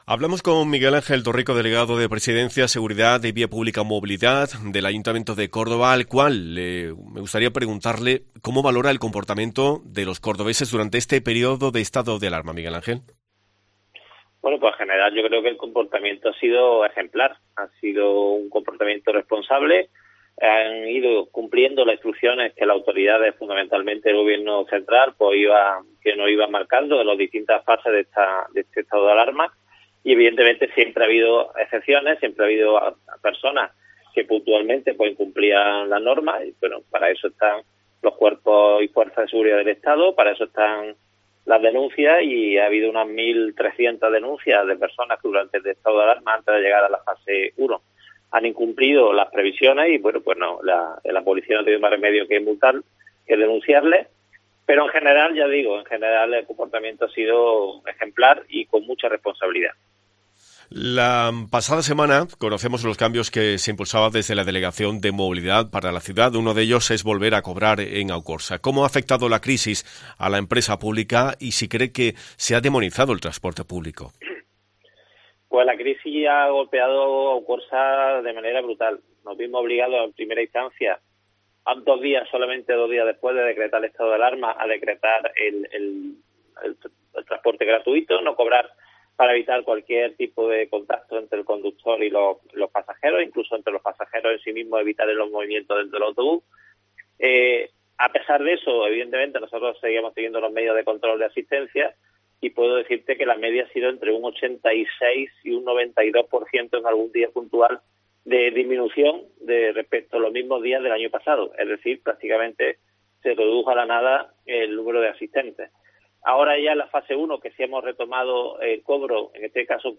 El delegado de Presidencia en el Ayuntamiento de Córdoba, Miguel Ángel Torrico (PP), ha asegurado en COPE que con el plan de desescalada "debemos seguir actuando con responsabilidad y pensando en los demás", porque todo lo contrario sería dar pasos atrás en lo que se lleva avanzado.